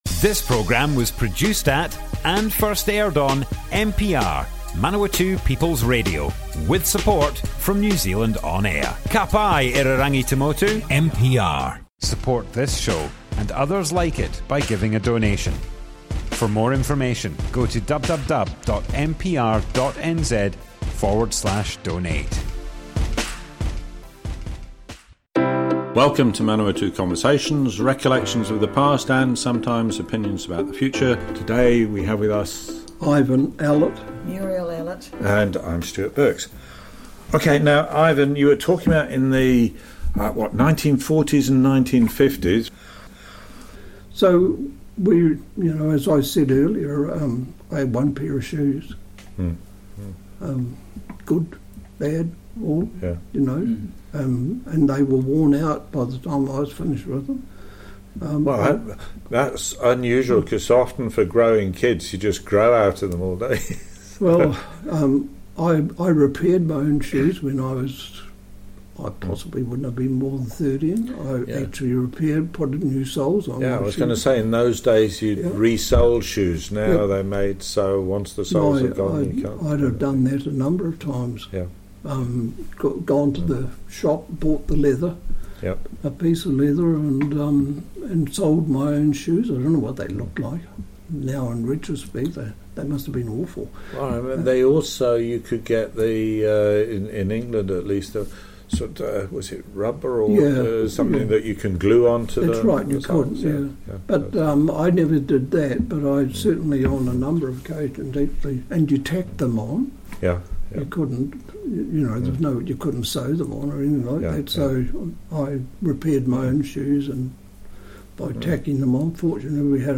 Manawatu Conversations Object type Audio More Info → Description Broadcast on Manawatu People's Radio 14th June 2022.
oral history